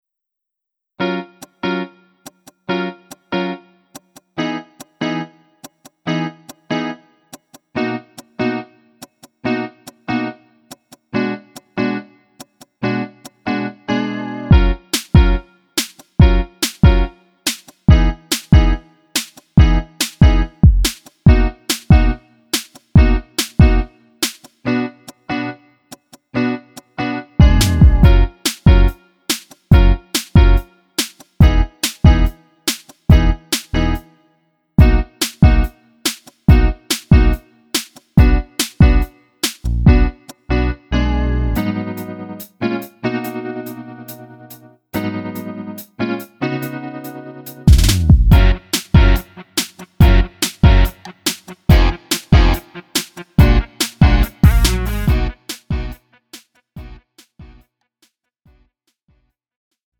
음정 -1키 2:54
장르 가요 구분 Lite MR
Lite MR은 저렴한 가격에 간단한 연습이나 취미용으로 활용할 수 있는 가벼운 반주입니다.